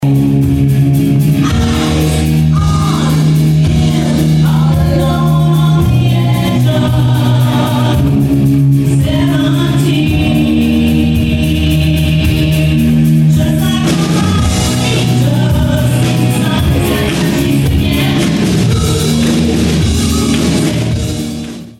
a rousing rendition